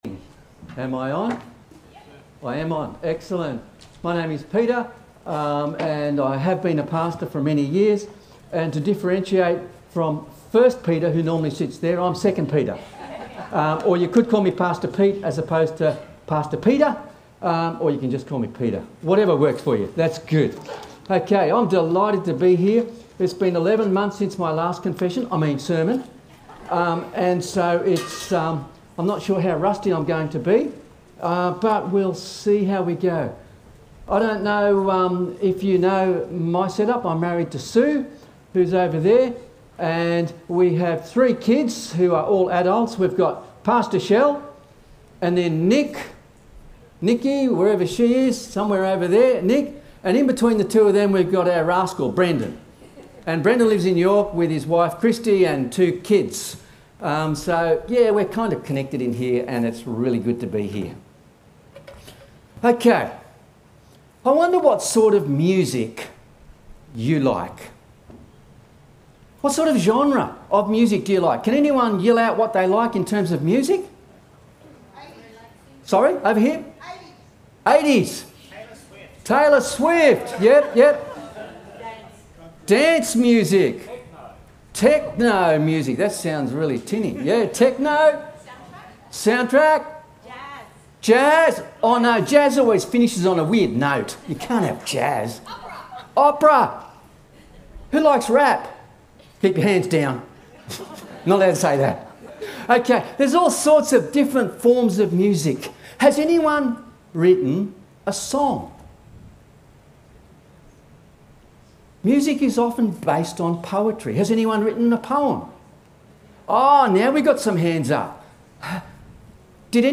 A message from the series "Songs of thanksgiving."